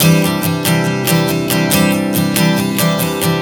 Strum 140 C 03.wav